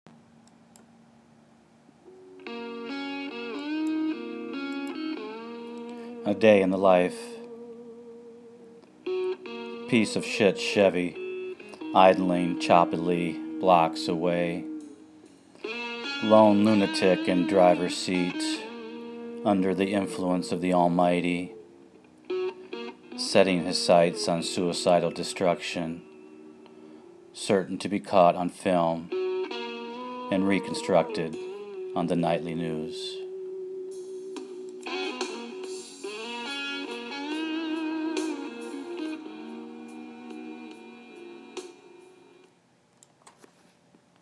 Author’s Note: The accompanying instrumental was performed by Jeff Beck from ‘In My Life,’ an album compiled and produced by Sir George Martin in 1988.